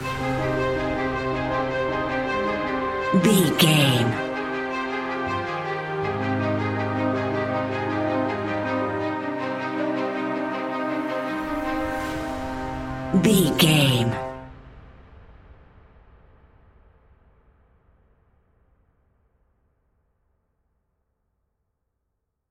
royalty free music
Aeolian/Minor
ominous
suspense
eerie